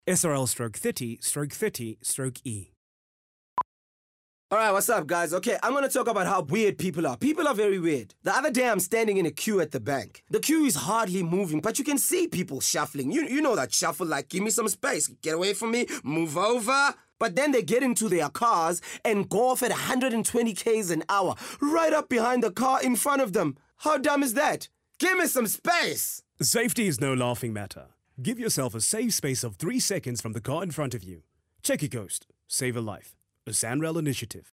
SANRAL Radio Ad 2